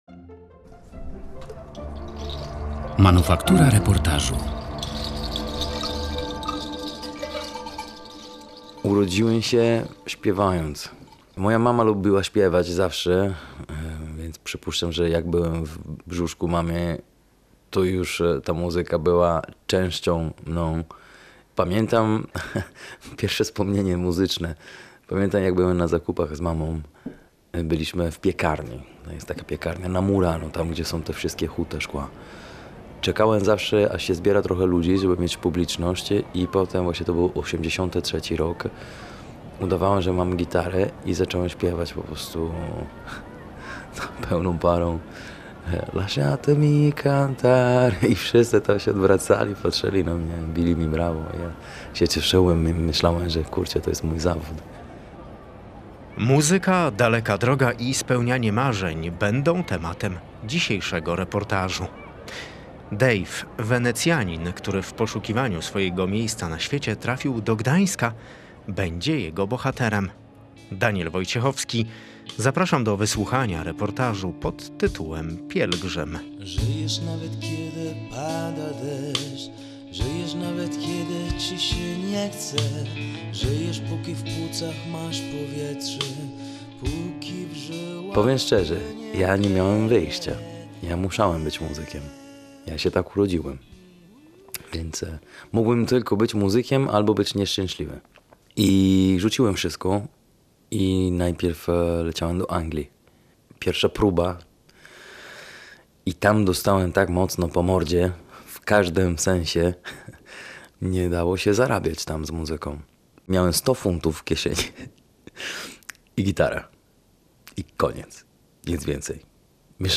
Ze słonecznej Italii trafił do Gdańska. Posłuchaj reportażu „Pielgrzym”